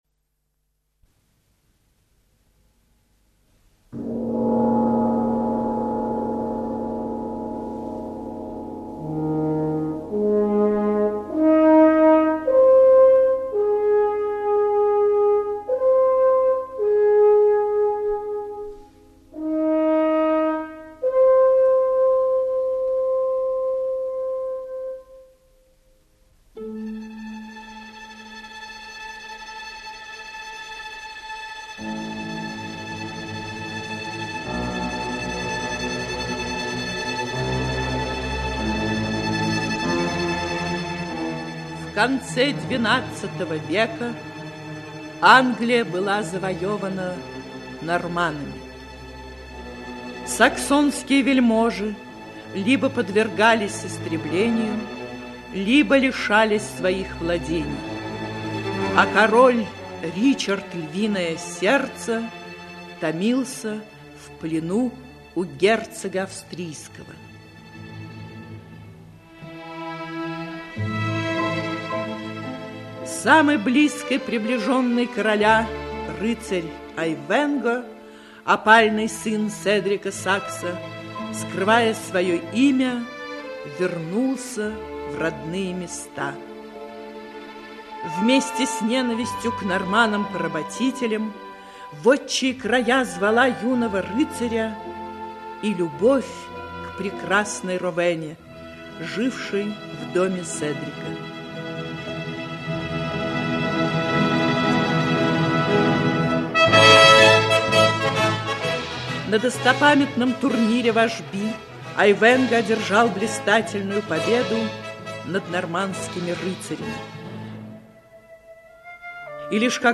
Аудиокнига Айвенго (спектакль) Часть 2-я. Черный рыцарь | Библиотека аудиокниг
Aудиокнига Айвенго (спектакль) Часть 2-я. Черный рыцарь Автор Вальтер Скотт Читает аудиокнигу Актерский коллектив.